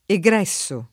[ e g r $SS o ]